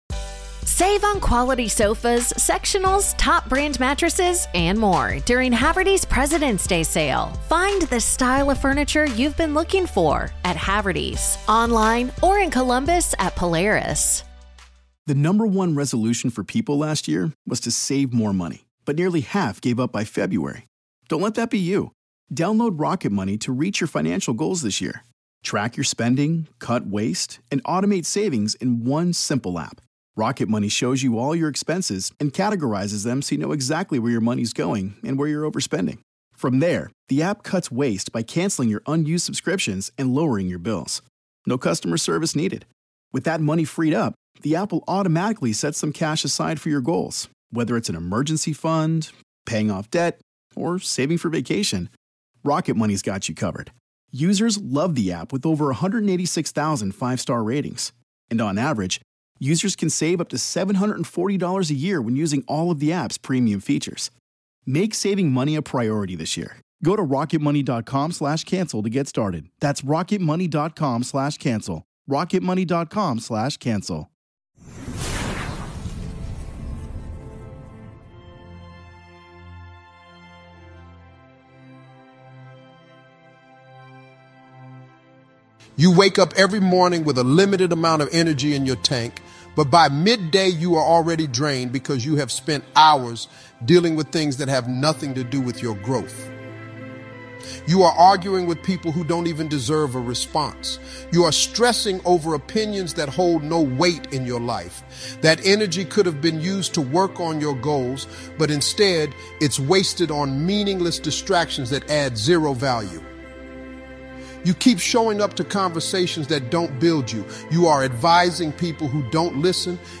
Are you tired of living below your potential? This powerful motivational speech will wake you up and remind you that it’s time to focus, level up, and value yourself like never before.